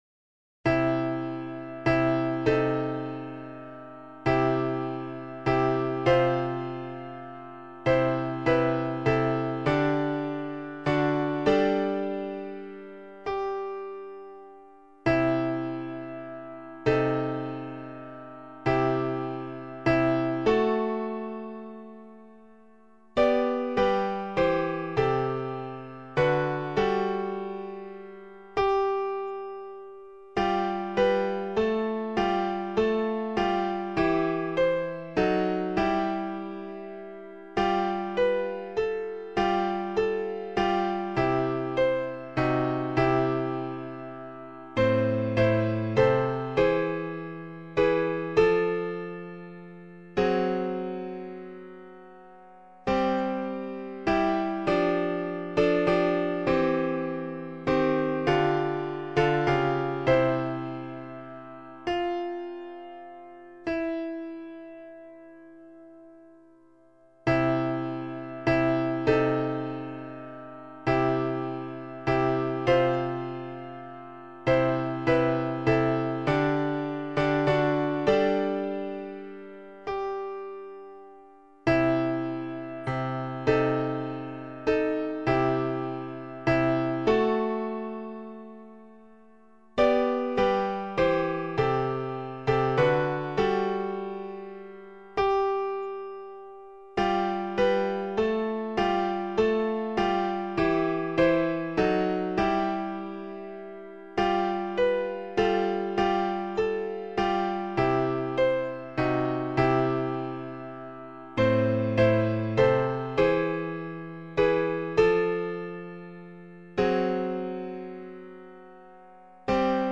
SAB